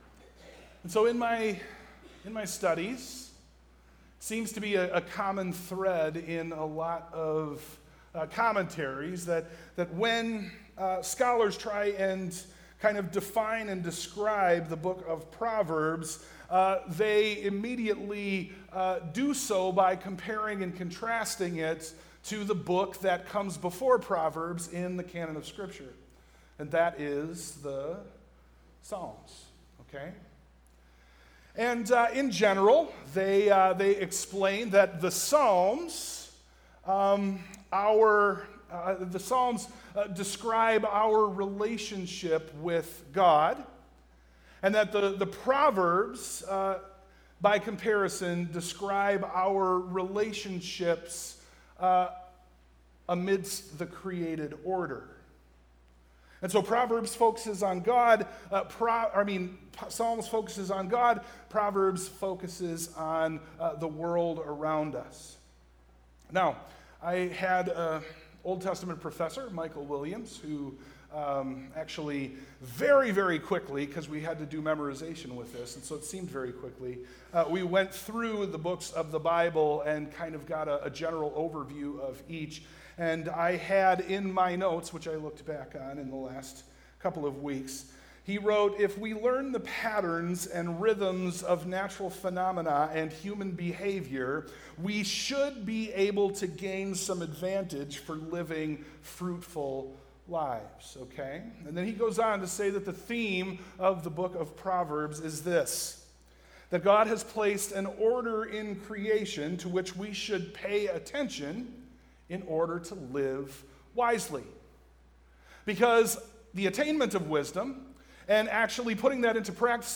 Service Type: AM
Sermon+Audio+-+Two+Voices.mp3